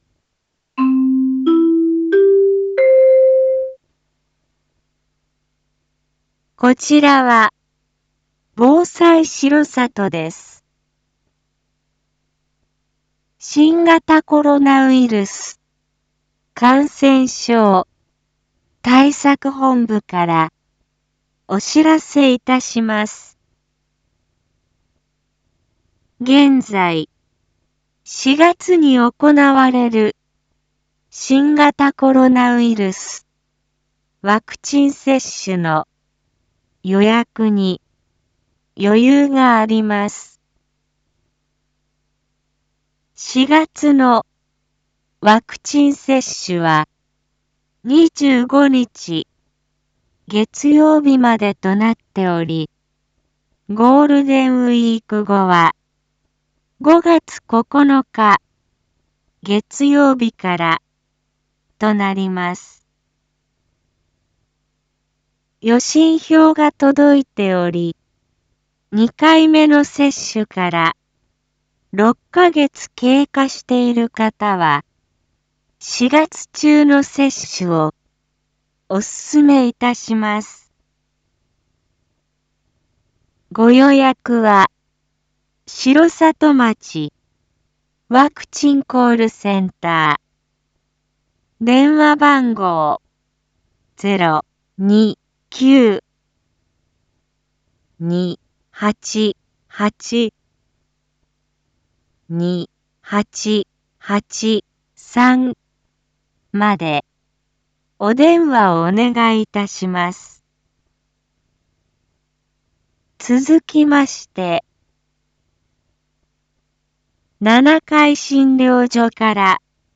一般放送情報
Back Home 一般放送情報 音声放送 再生 一般放送情報 登録日時：2022-04-07 07:02:54 タイトル：R4.4.7 19時放送分 インフォメーション：こちらは、防災しろさとです。 新型コロナウイルス感染症対策本部から、お知らせいたします。